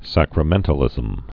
(săkrə-mĕntl-ĭzəm)